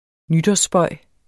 Udtale [ ˈnydʌs- ]